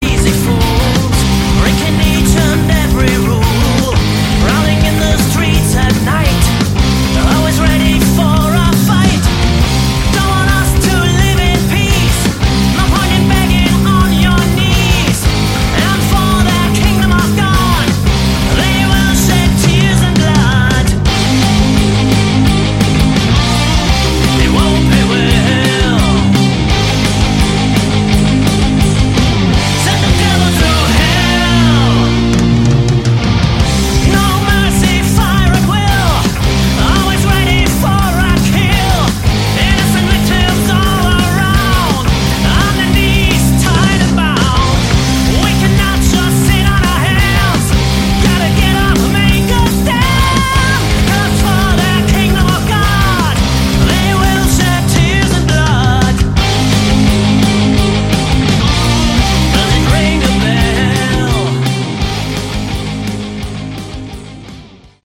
Category: Hard Rock
Vocals, Lead Guitar
Vocals, rhythm guitar
Drums
Vocals, Bass